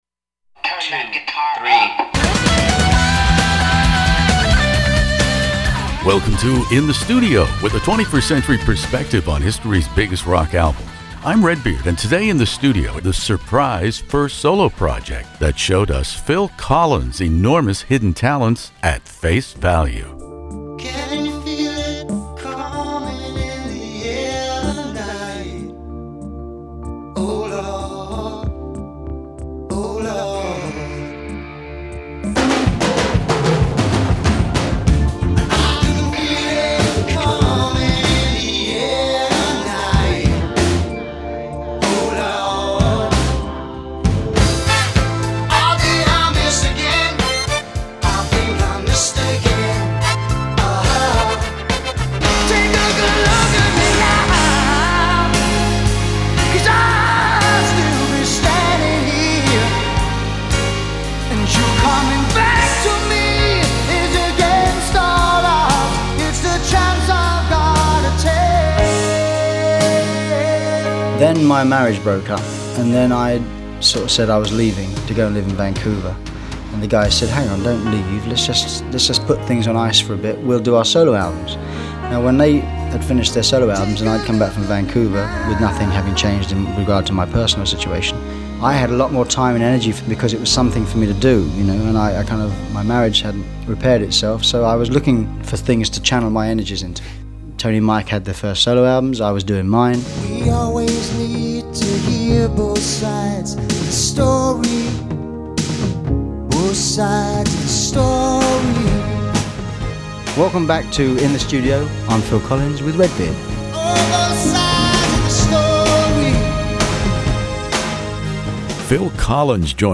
Phil Collins Face Value interview In the Studio